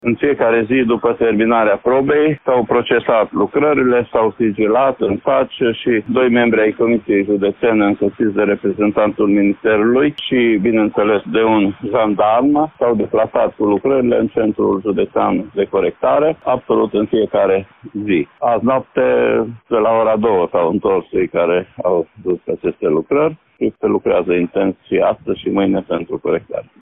Inspectorul școlar general Ștefan Someșan.